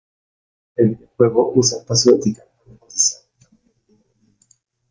vi‧de‧o‧jue‧go
/bideoˈxweɡo/